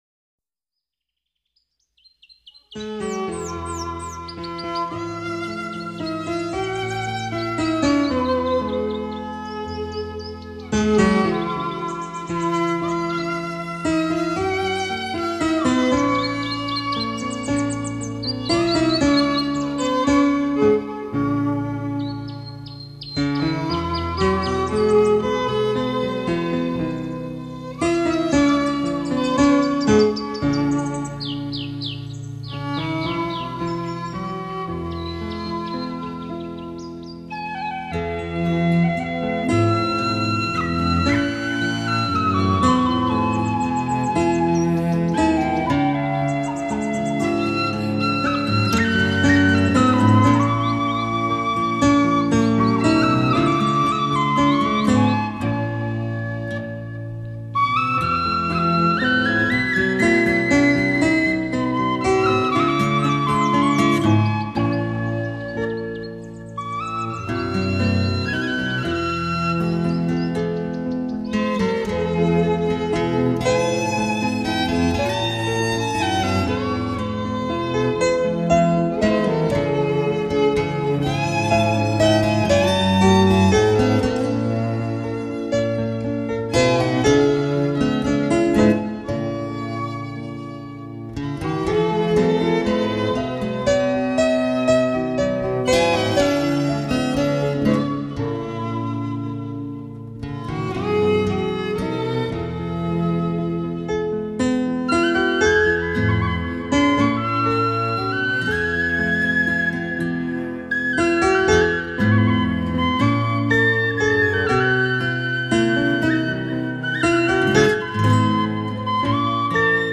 Genre: World/Celtic